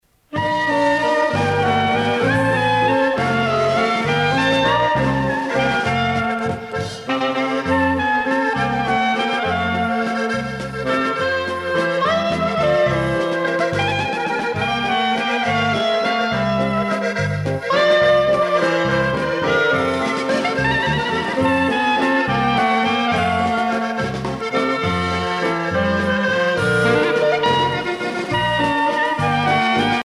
valse musette
Orchestre de variétés
Pièce musicale éditée